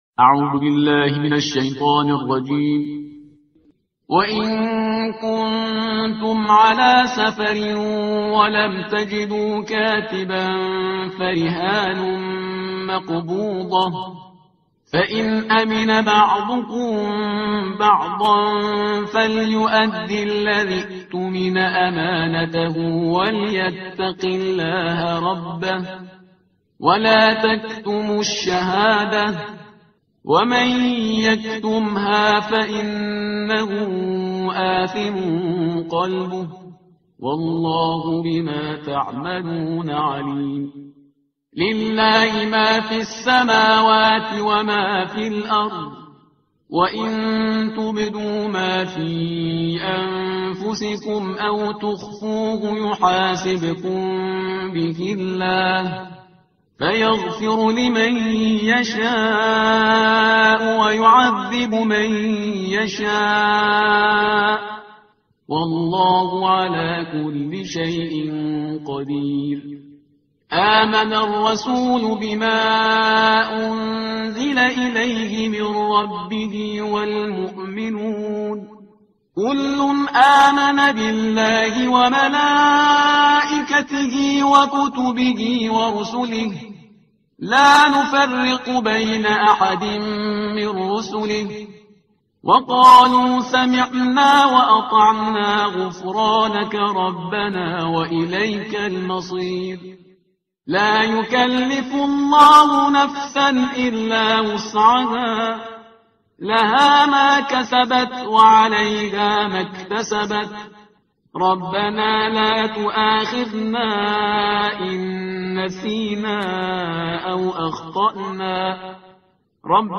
ترتیل صفحه 49 قرآن با صدای شهریار پرهیزگار